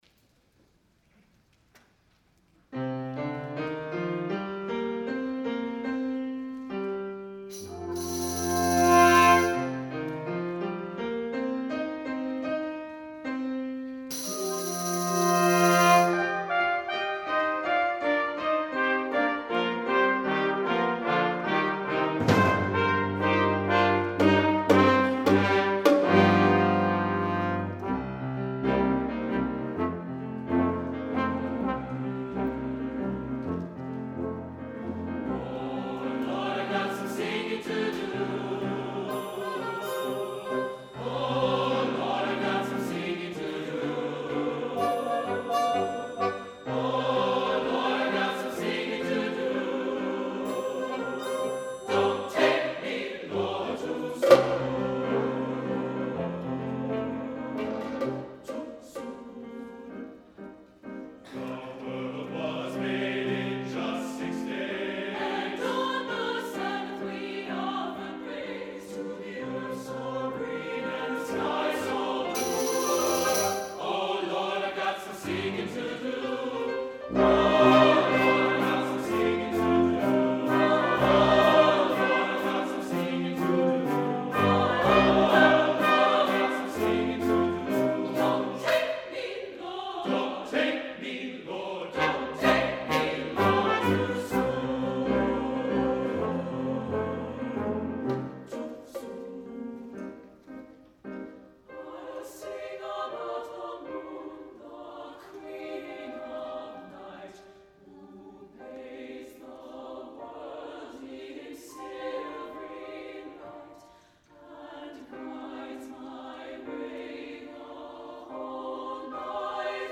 for SATB Chorus, Brass Quintet, Percussion, and Piano (2011)